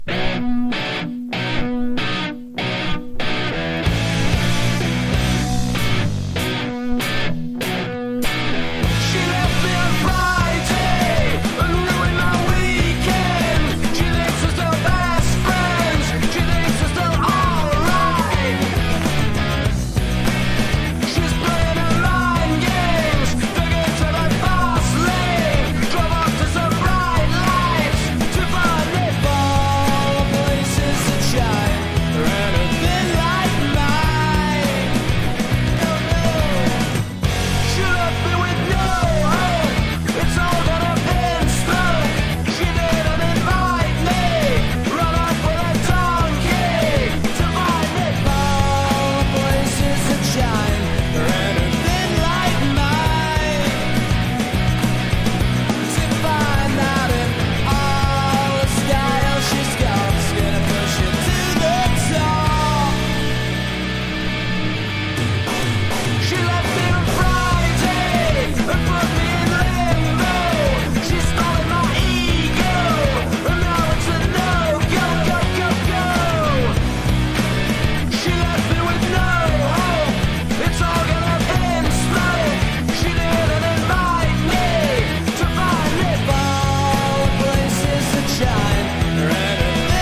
1. 90'S ROCK >
これぞブリット・ポップといった感じのベース・ライン、メロディー!!